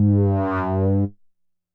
bass note03.wav